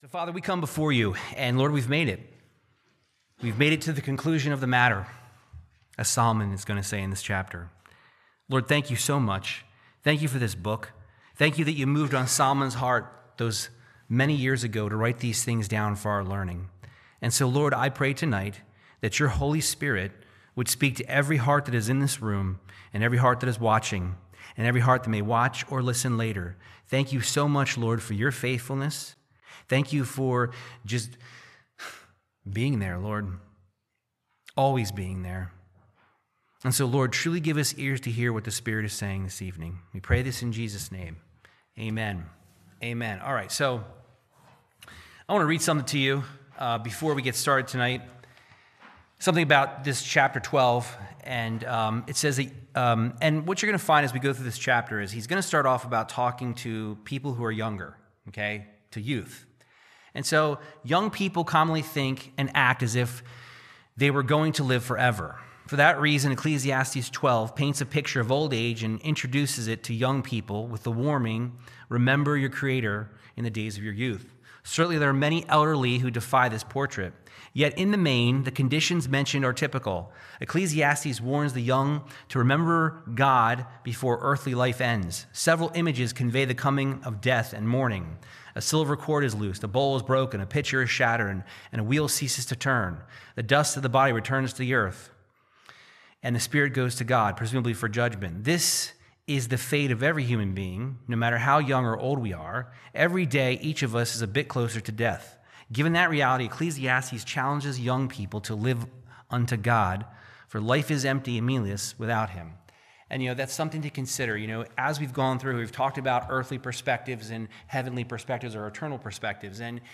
Verse by Verse Bible Teaching of Ecclesiastes 12